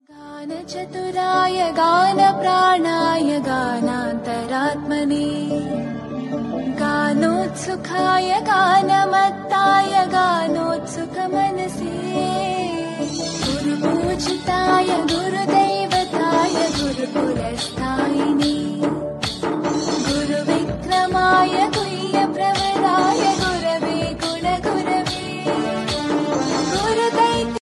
best flute ringtone download